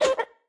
Media:Chicken_base_atk_1.wavMedia:Chicken_base_atk_2.wavMedia:Chicken_base_atk_3.wavMedia:Chicken_base_atk_4.wavMedia:Chicken_base_atk_5.wavMedia:Chicken_base_atk_6.wav 攻击音效 atk 经典及以上形态攻击音效
Chicken_base_atk_1.wav